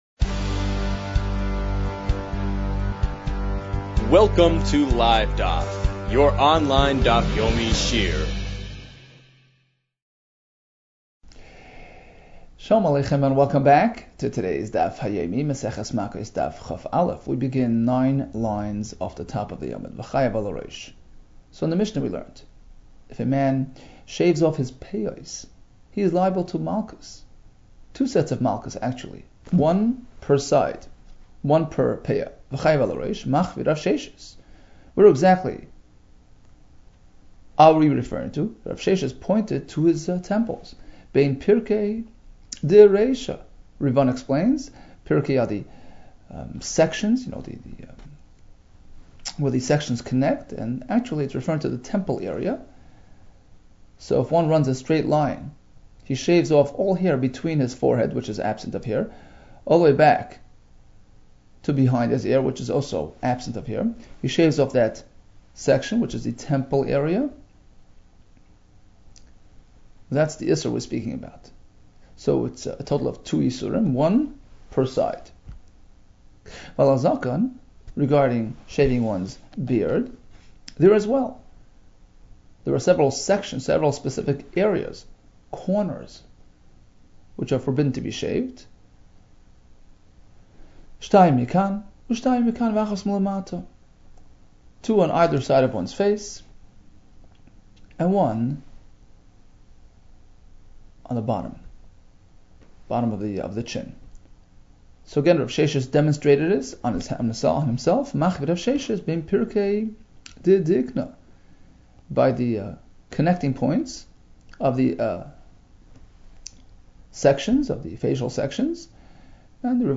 Makkos 20 - מכות כ | Daf Yomi Online Shiur | Livedaf